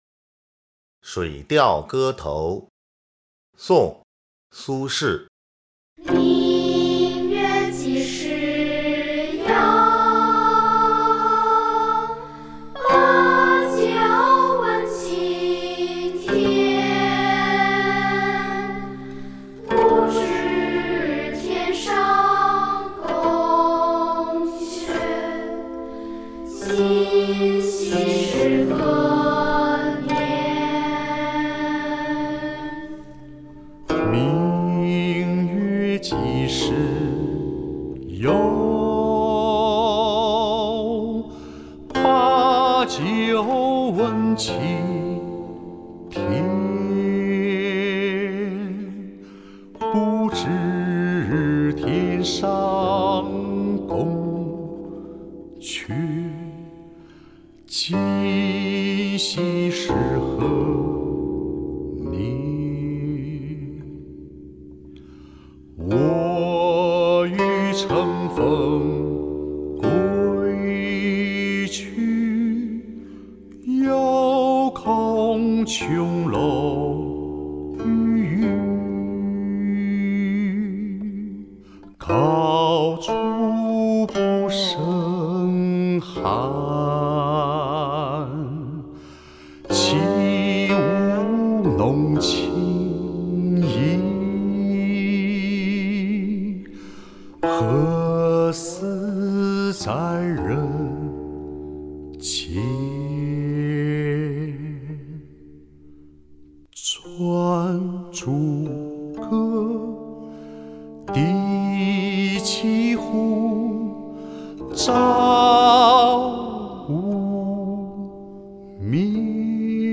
［宋］苏轼 《水调歌头》（明月几时有）（吟咏）